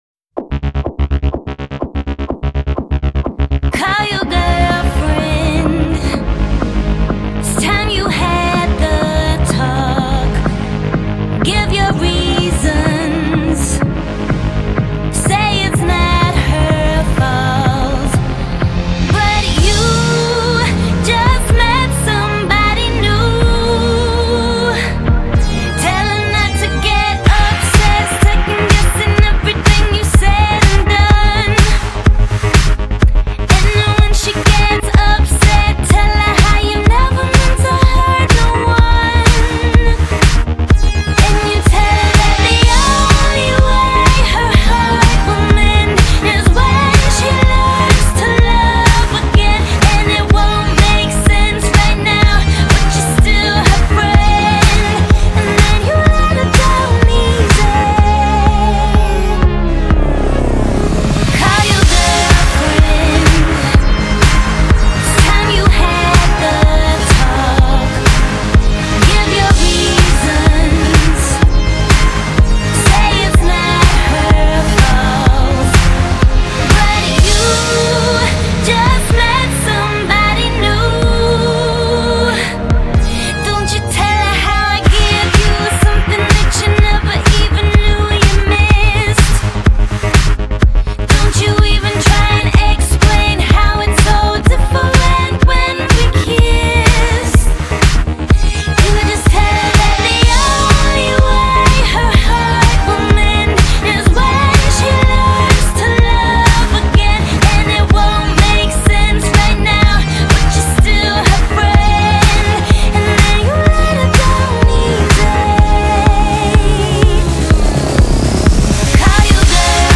Swedish dance-pop queen
strong melodic hooks and a powerful emotional connection.